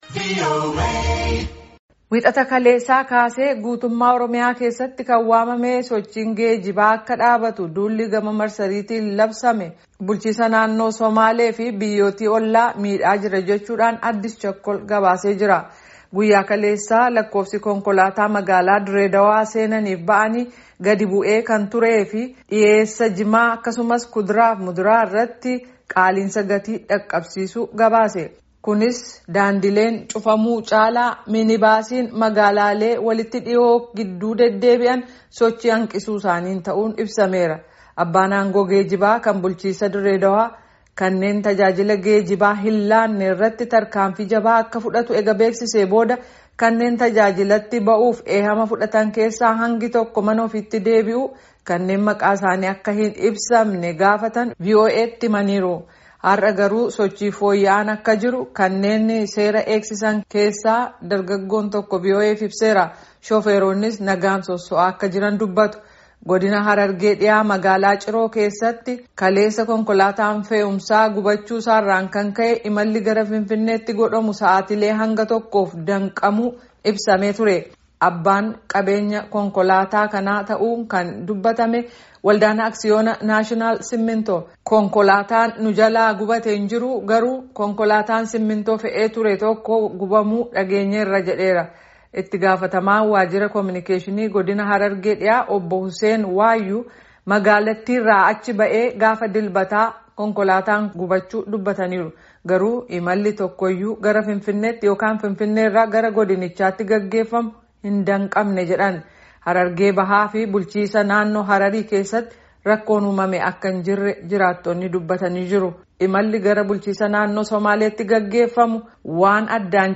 Harargee bahaa fi bulchiinsa naannoo Hararii keessa rakkoon uumamee akka hin jirre beekameera jedha oduu gabaasaan raadiyoo sagakee Amerikaa kun.